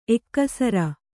♪ ekkasara